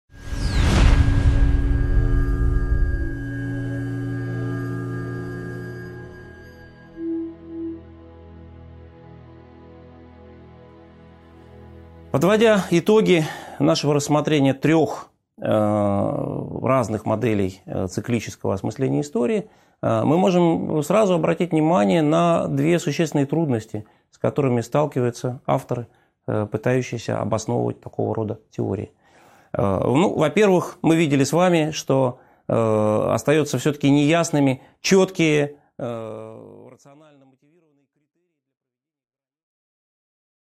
Аудиокнига 13.6 Трудности обоснования циклических теорий | Библиотека аудиокниг